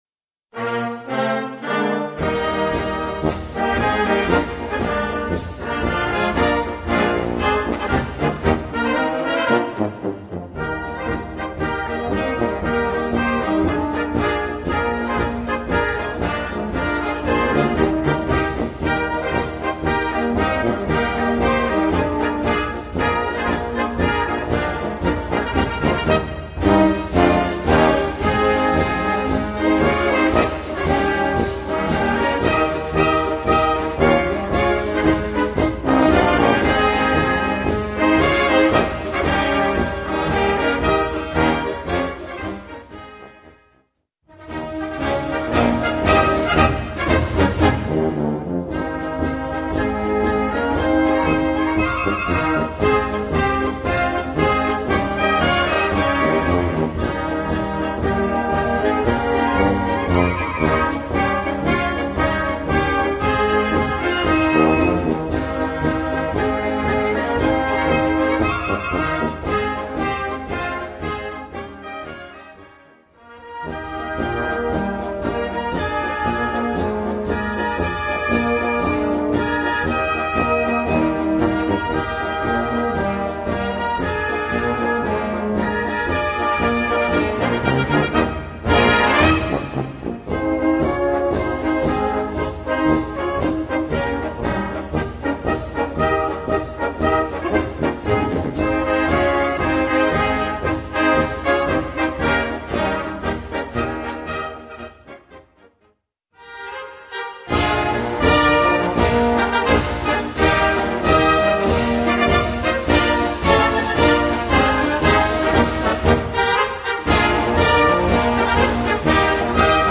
Gattung: Polkamelodien
Besetzung: Blasorchester
Polka-Potpourri